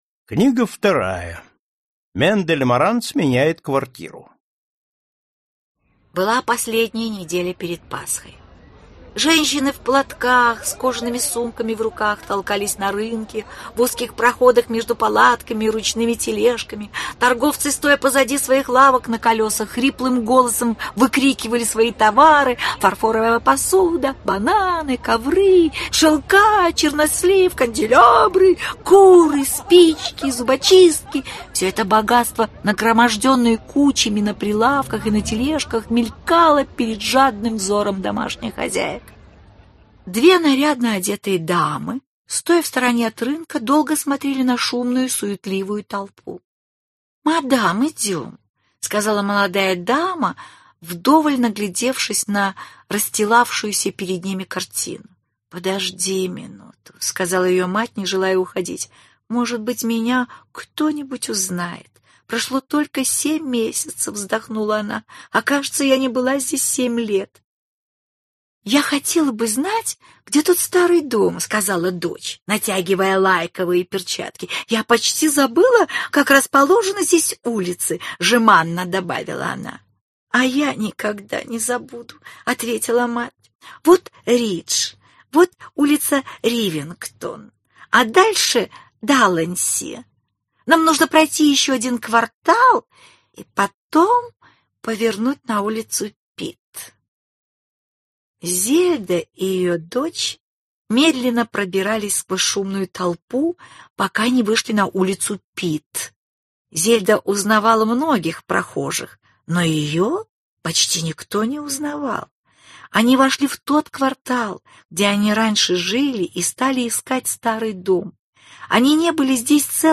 Аудиокнига Мендель Маранц. Книга вторая. Мендель Маранц меняет квартиру | Библиотека аудиокниг